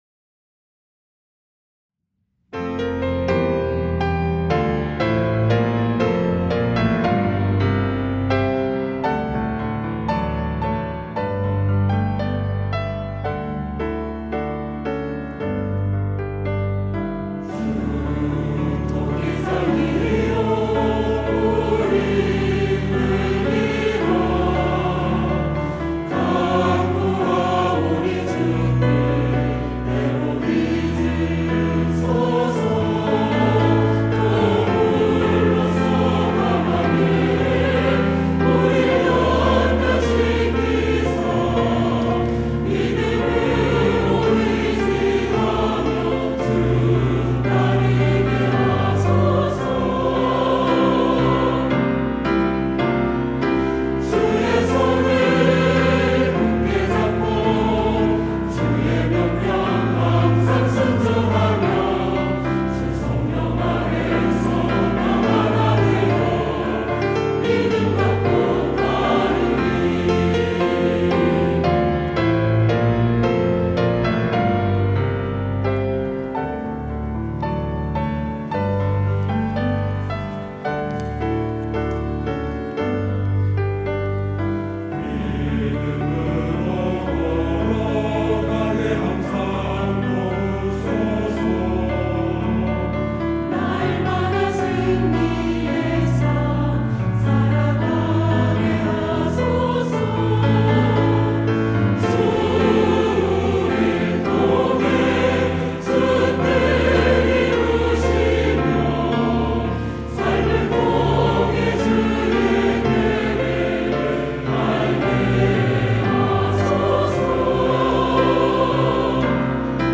시온(주일1부) - 믿음으로 나아가
찬양대